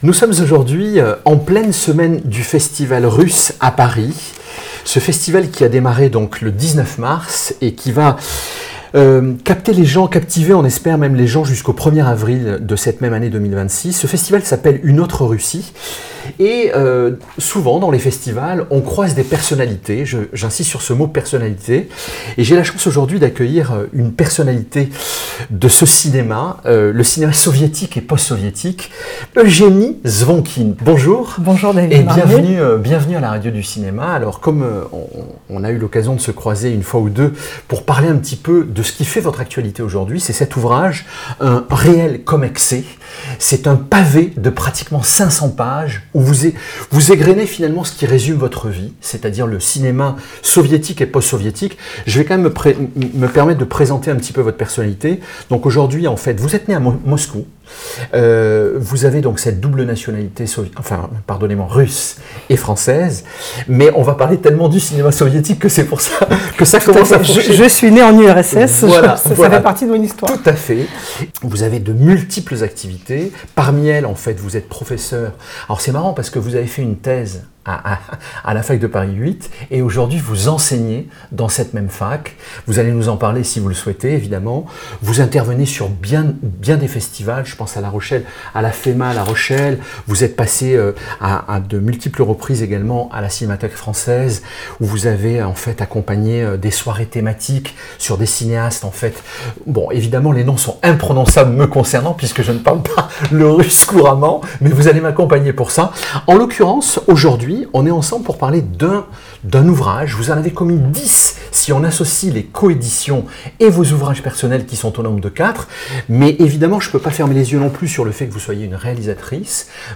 %%Les podcasts, interviews, critiques, chroniques de la RADIO DU CINEMA%% La Radio du Cinéma